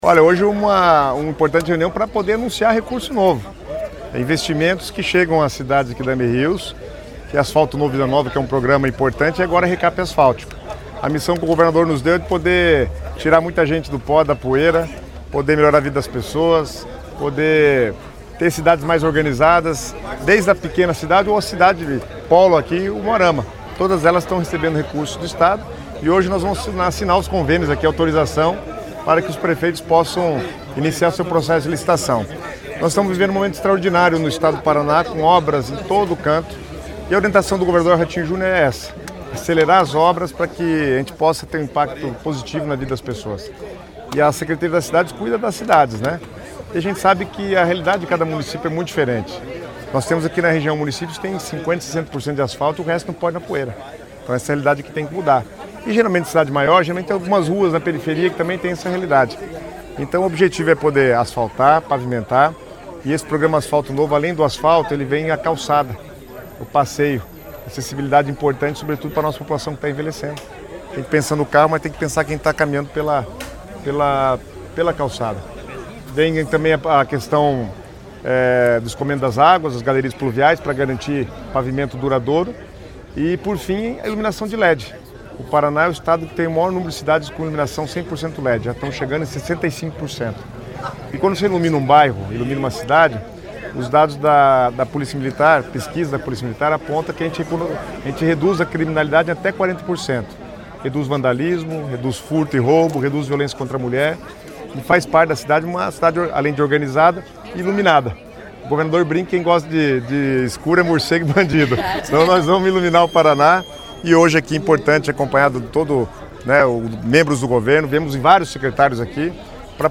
Sonora do secretário Estadual das Cidades, Guto Silva, sobre o repasse de R$ 322 milhões a 21 municípios de Amerios, no Noroeste